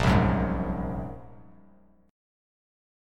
Bb7sus2#5 chord